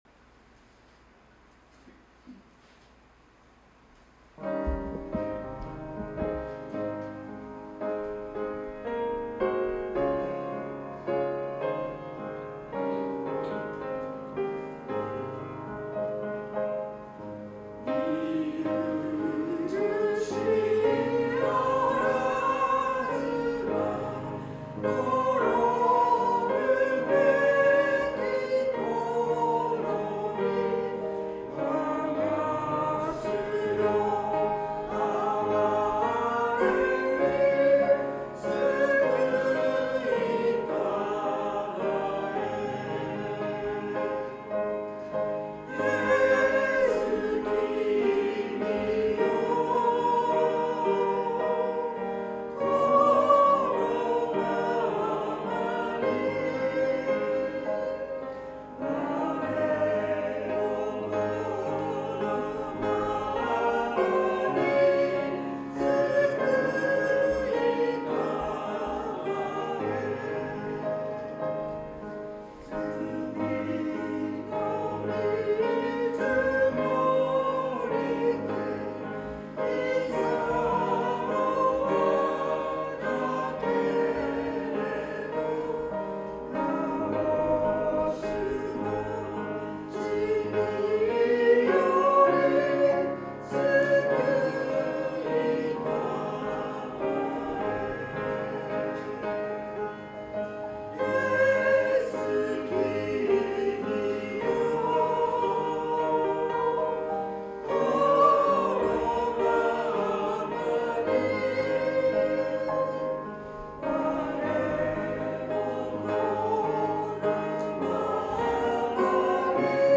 2月9日聖歌隊賛美「みゆるしあらずば」